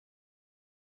door.mp3